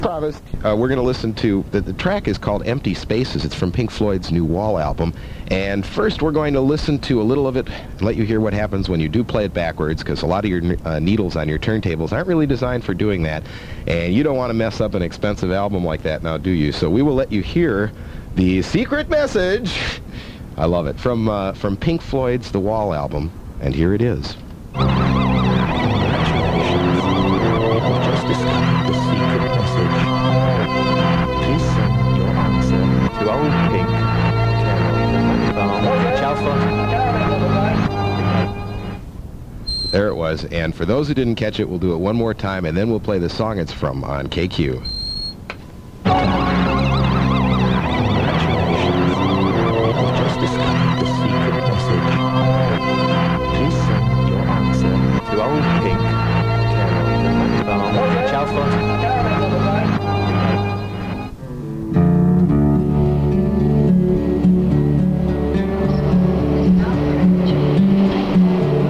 The quality of this is not as good as most of my other recordings.  It wasn’t recorded in stereo or on high grade tape.
In this case, it was a Panasonic mono AM/FM/cassette portable unit and a Radio Shack Concertape 90 minute cassette.